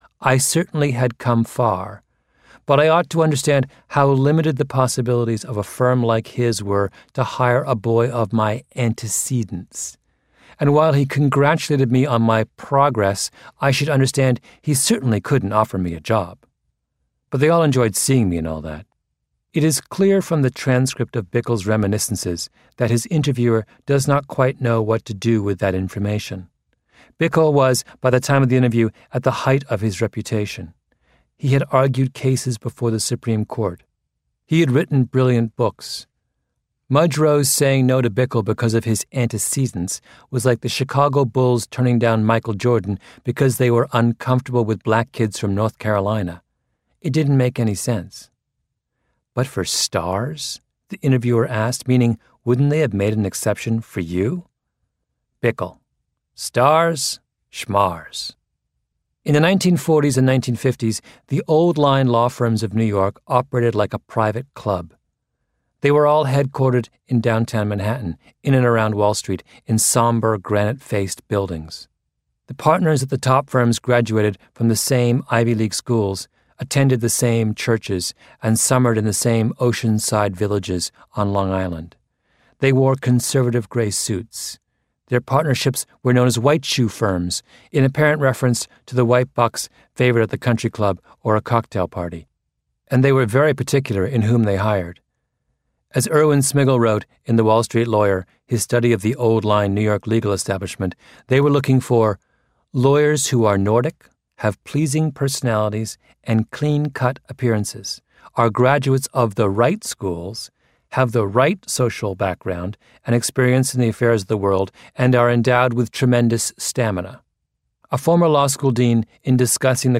在线英语听力室异类之不一样的成功启示录 第80期:纽约律师所的出身歧视的听力文件下载, 《异类:不一样的成功启示录Outliers:The Story of Success》是外文名著，是双语有声读物下面的子栏目，栏目包含中英字幕以及地道的英语音频朗读文件MP3，通过学习本栏目，英语爱好者可以懂得不一样的成功启示，并在潜移默化中挖掘自身的潜力。